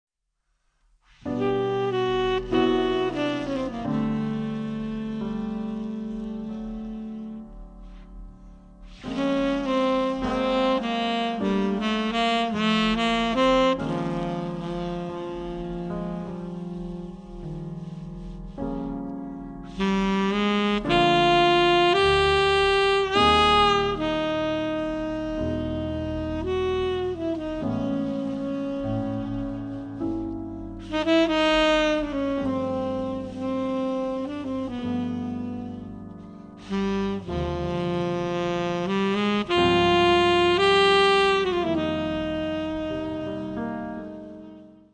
tenor and soprano saxophones
piano and Fender Rhodes
bass
drums